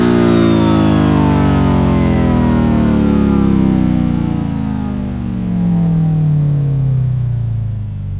v8_14_decel.wav